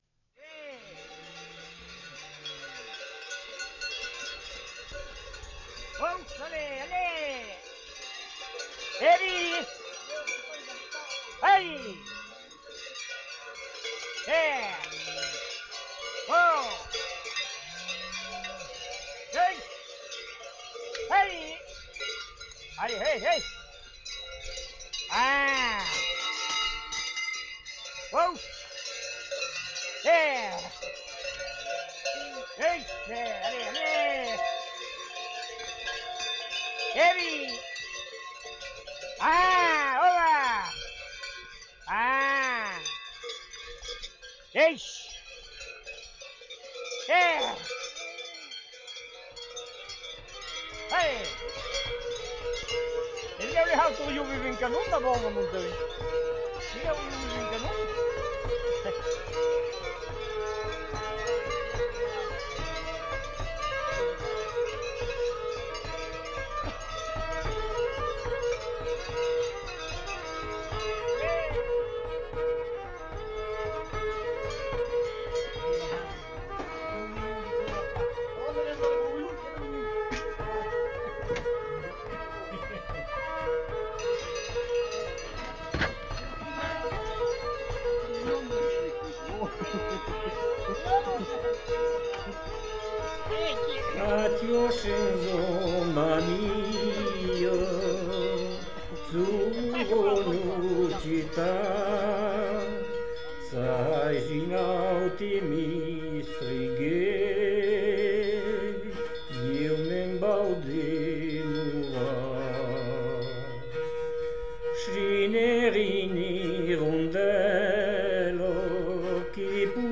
Musique de Sonnailles
Musique-de-Sonnailles.mp3